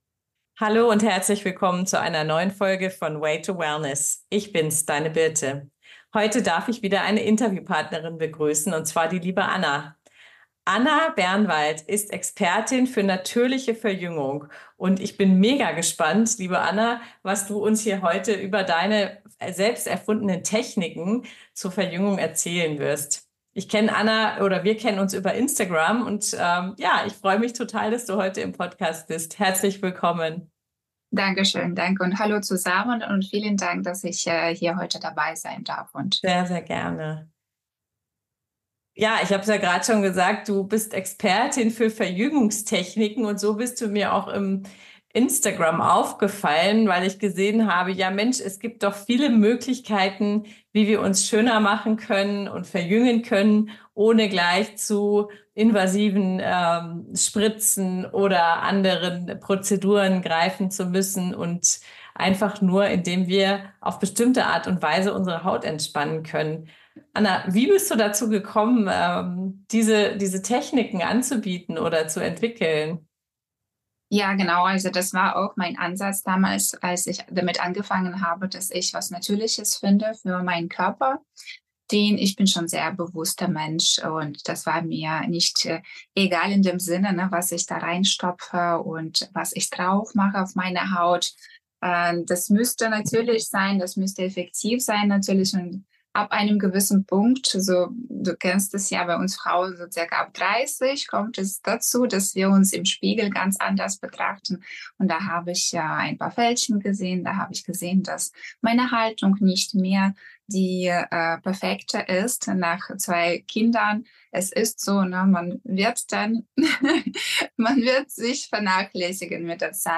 Die Expertin für natürliche Verjüngung – Interview